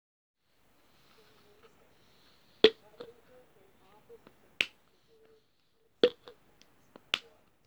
Het open en dicht doen van een potje.